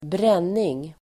Uttal: [br'en:ing]